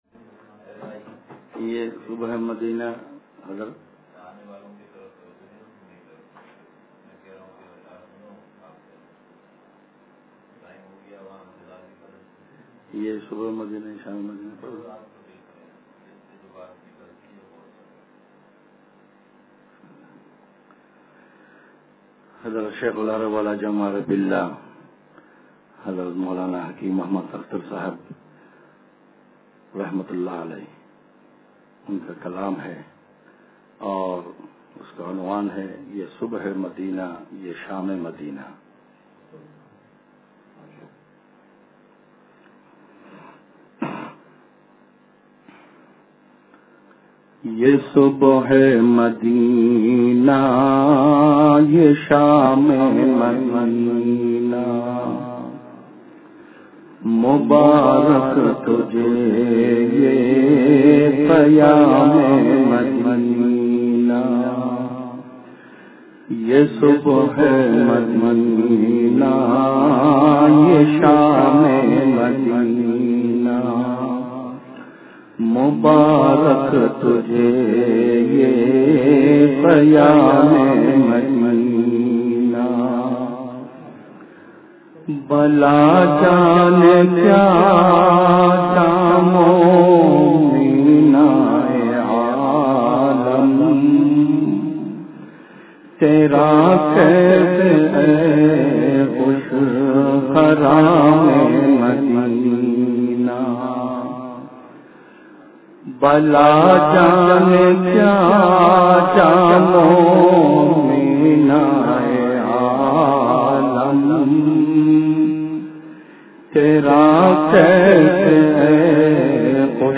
یہ صبح مدینہ یہ شامِ مدینہ – اتوار بیان